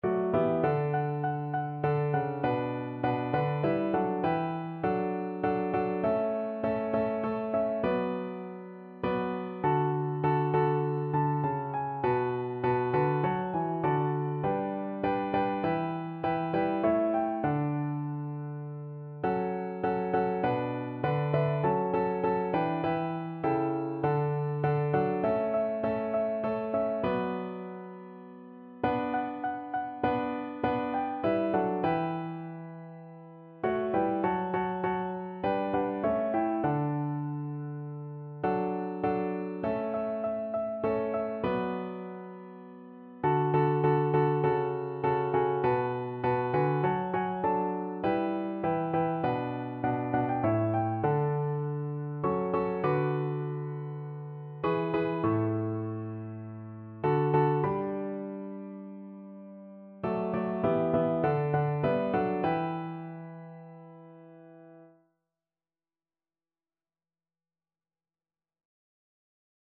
Free Sheet music for Piano Four Hands (Piano Duet)
4/4 (View more 4/4 Music)
Traditional (View more Traditional Piano Duet Music)